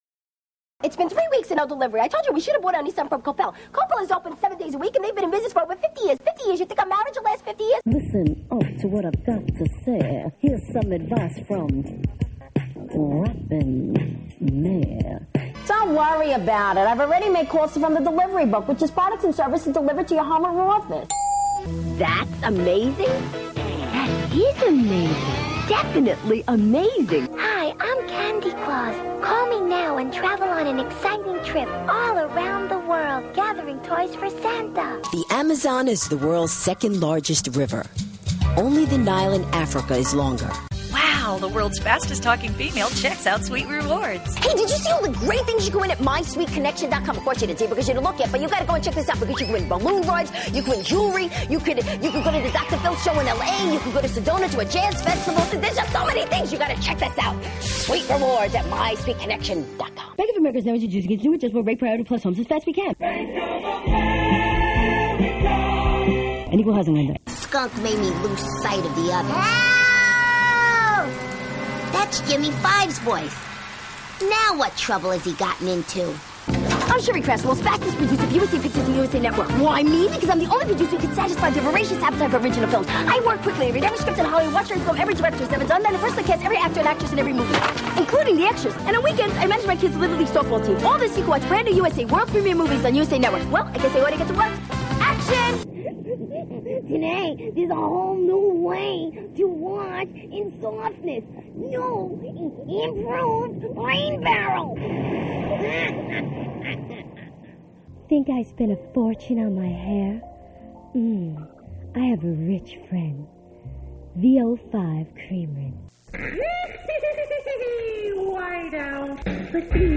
Fran Capos Voice Over samples
Tags: Media Fast Talkers Fast Tawkers Fran Capo World Record